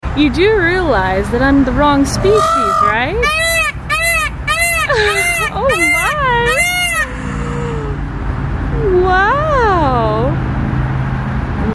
🦚💖A peacock I know very sound effects free download
🦚💖A peacock I know very well fell in love and serenaded me.